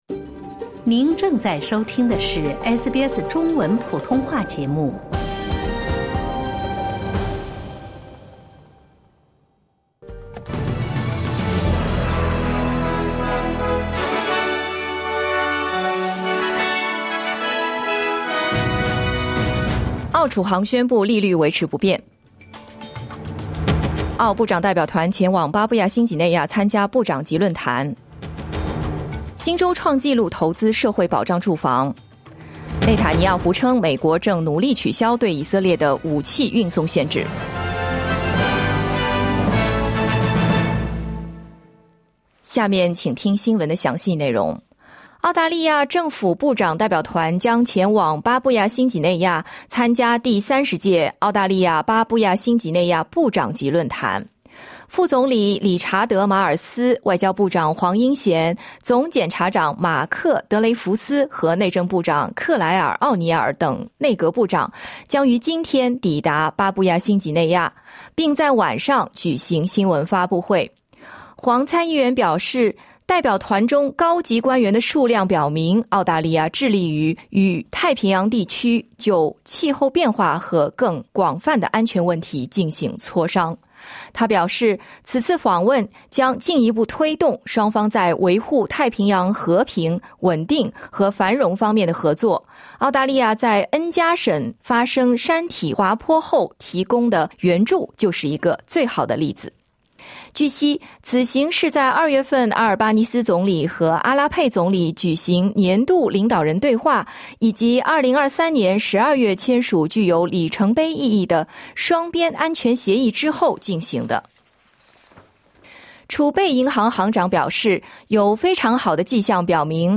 SBS早新闻 (2024年6月19日)